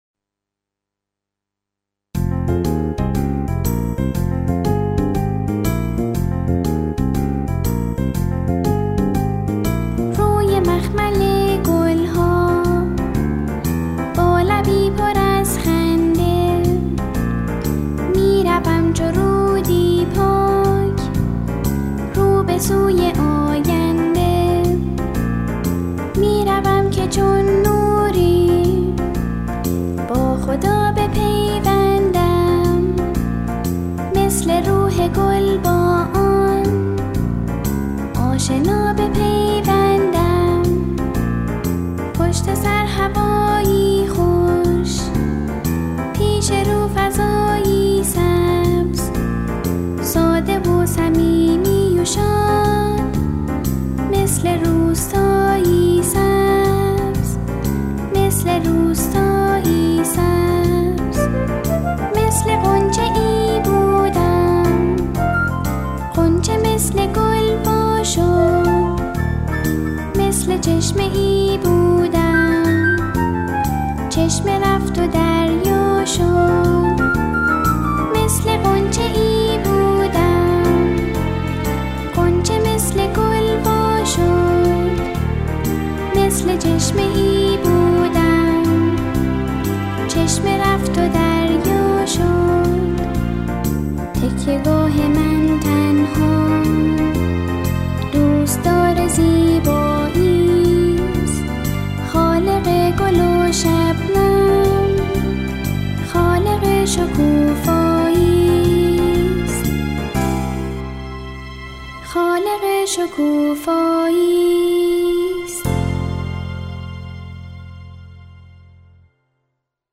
با اجرای تکخوان ناشناس